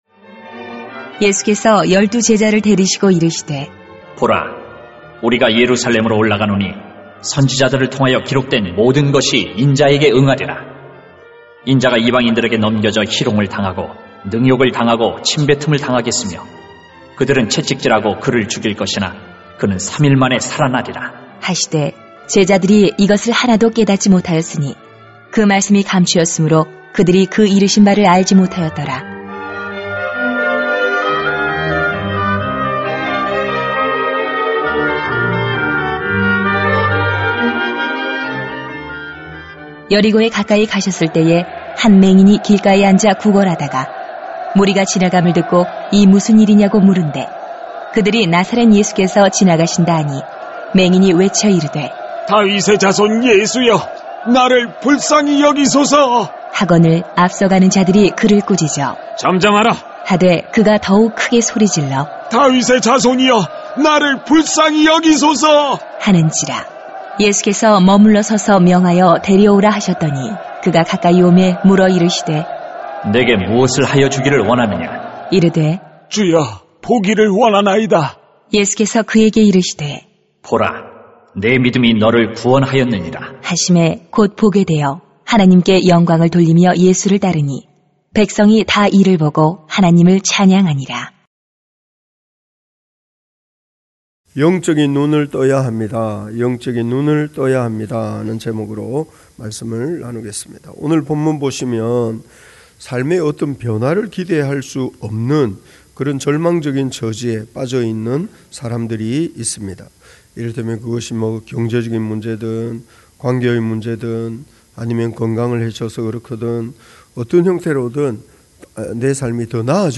[눅 18:31-43] 영적인 눈을 떠야 합니다 > 새벽기도회 | 전주제자교회